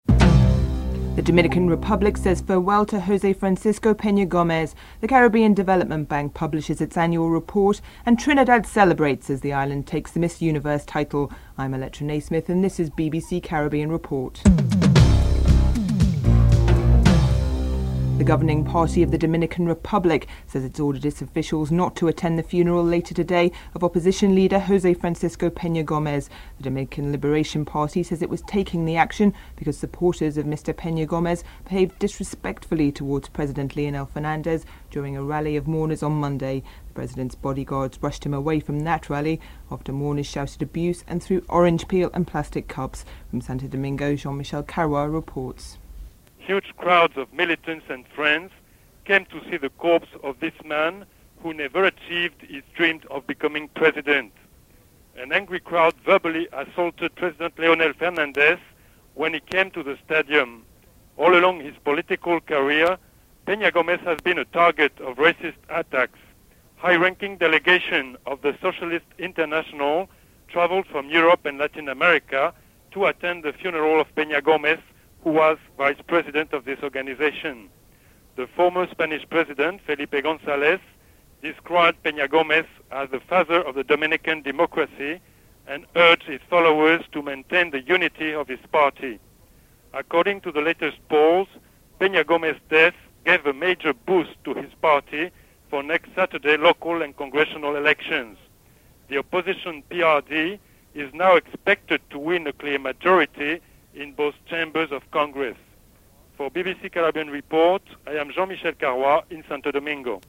The British Broadcasting Corporation